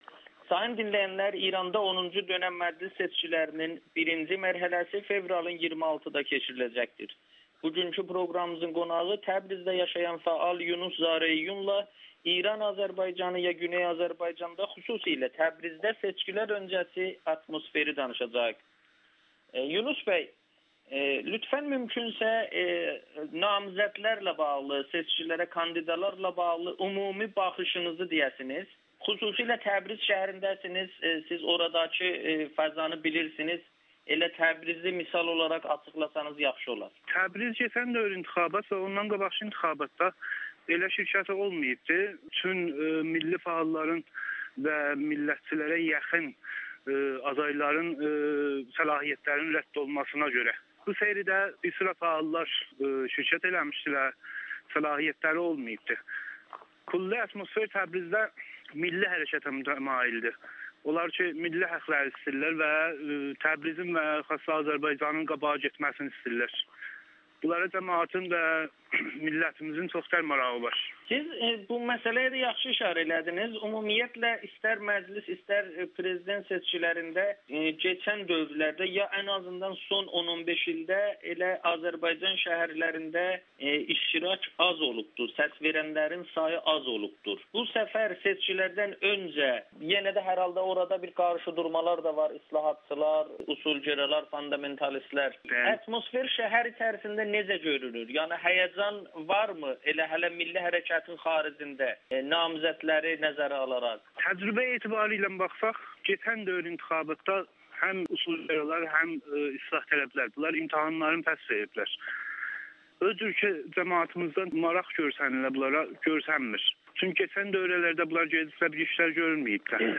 Təbrizin səsini milli haqlardan danışan namizədlər alacaq [Audio-Müsahibə]